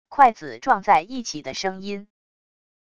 筷子撞在一起的声音wav音频